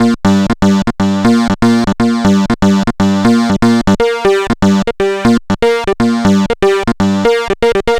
Session 08 - Synth Lead.wav